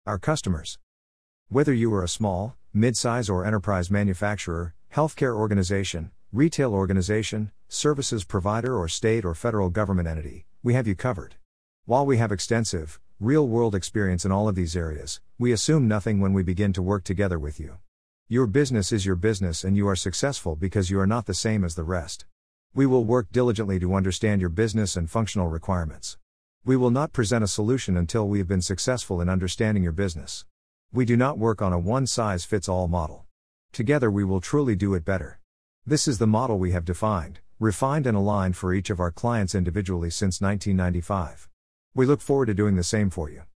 easytts_audio_Our-Customers1.mp3